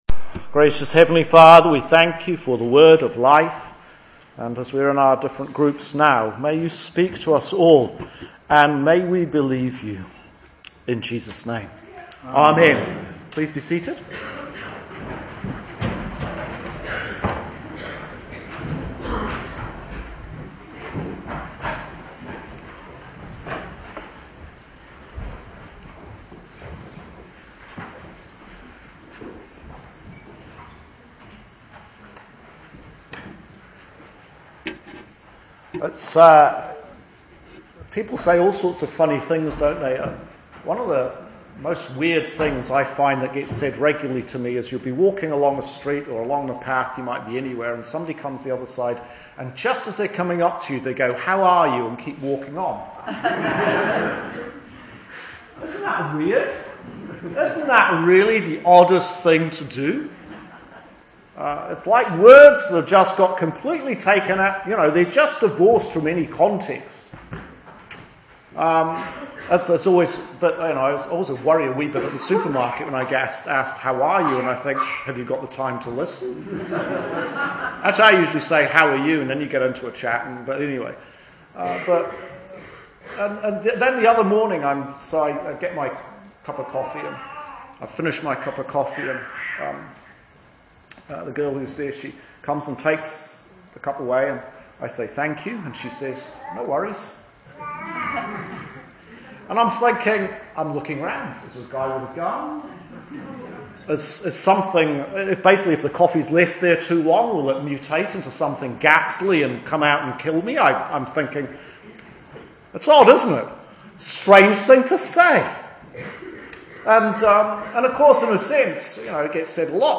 Sermon for the tenth Sunday after Trinity – Year C 2019